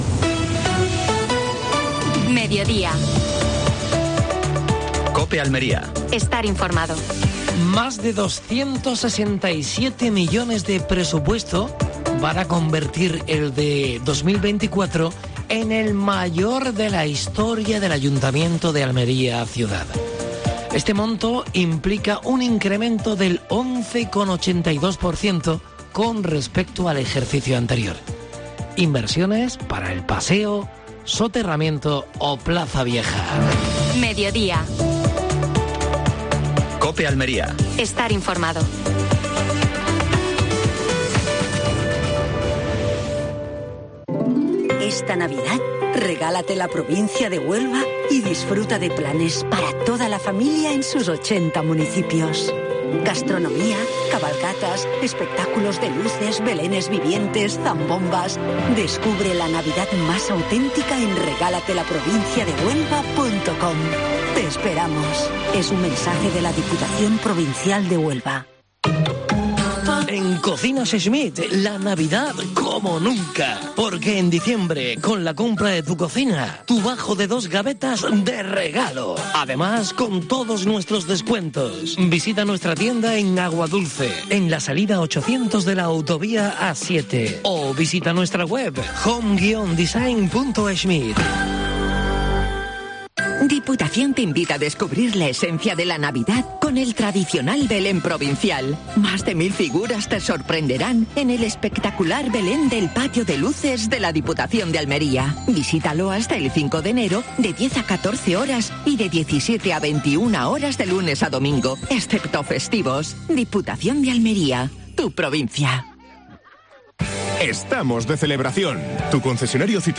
AUDIO: Actualidad en Almería. Entrevista a Alfonso García (alcalde de Vera).